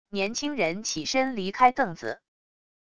年轻人起身离开凳子wav音频